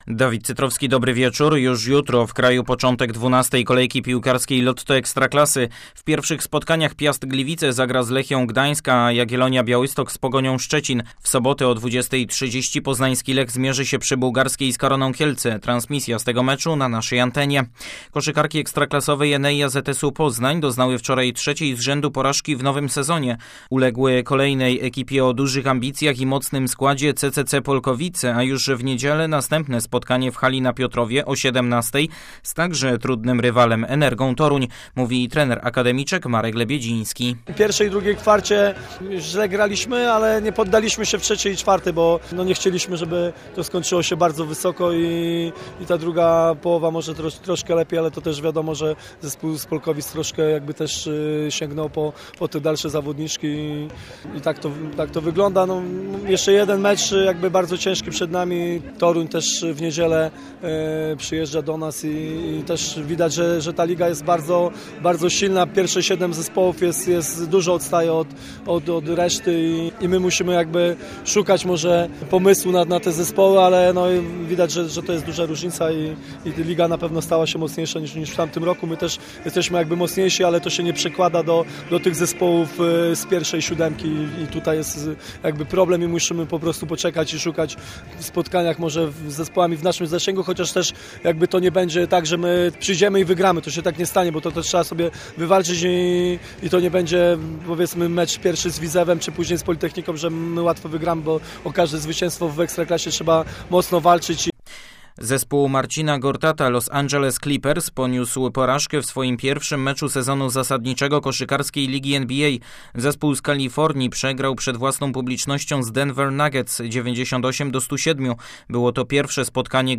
18.10. serwis sportowy godz. 19:05